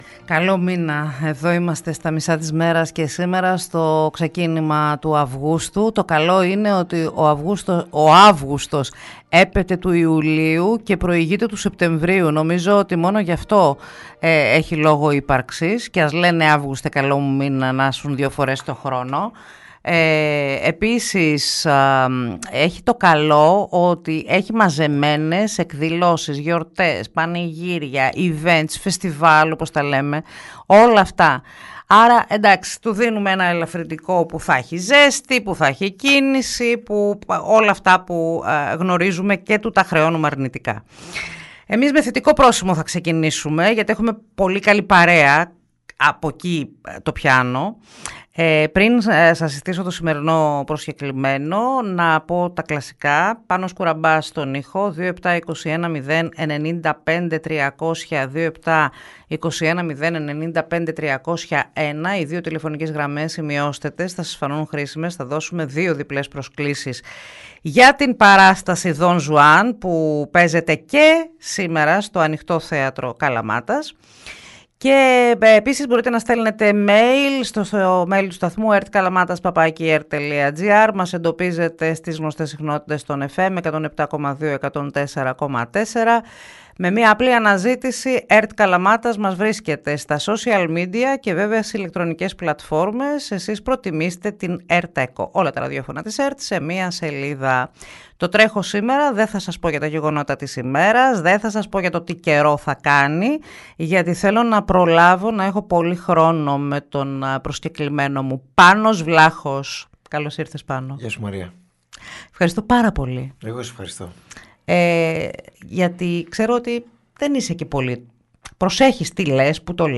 Ο Πάνος Βλάχος στο ραδιόφωνο της ΕΡΤ Καλαμάτας | 01.08.2025